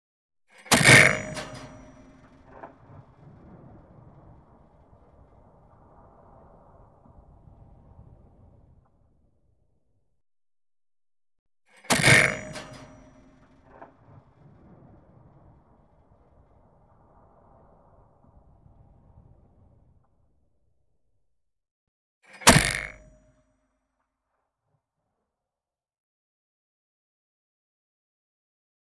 M-1 Tank Cannon: Recoils ( Int. ) ( 3x ); Tank Cannon Fires With Buzz Of Metal Components, Long And Distant Recoils; 3 Individual Shots Fire In Series. Medium Perspective.